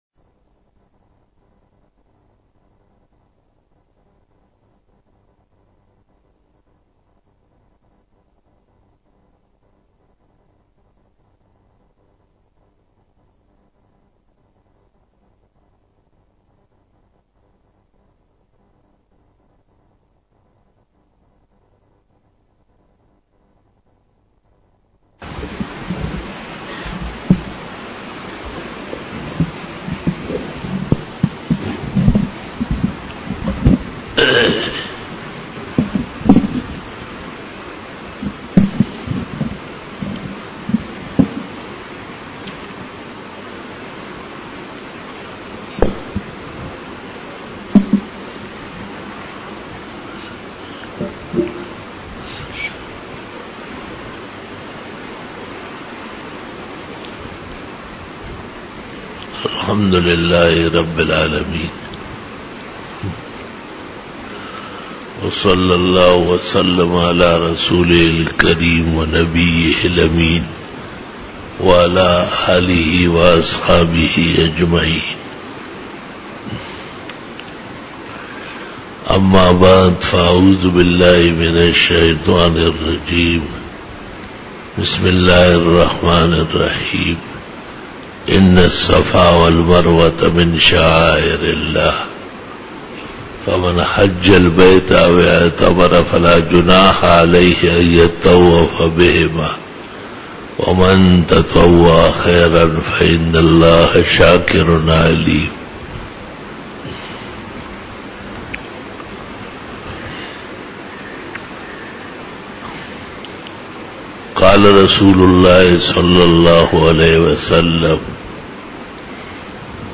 40 BAYAN E JUMA TUL MUBARAK (05 October 2018) (24 Muharram 1440H)